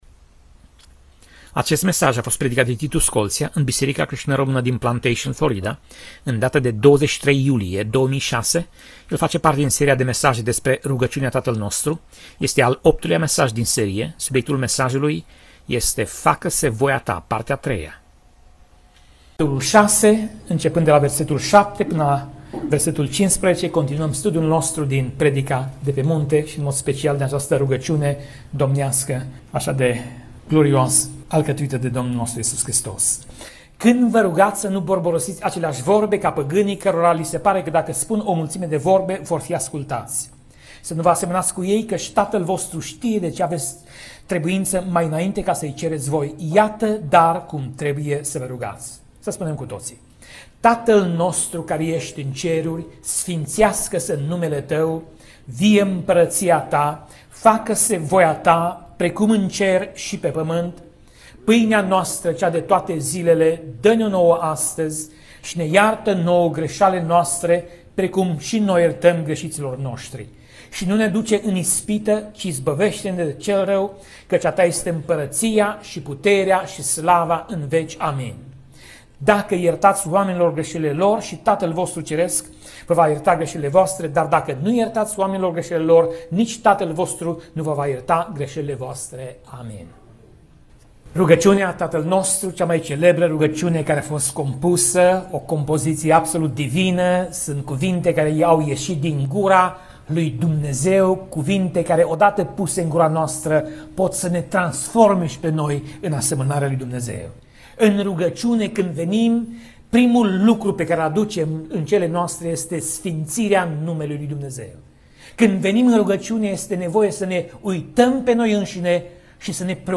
Pasaj Biblie: Matei 6:9 - Matei 6:13 Tip Mesaj: Predica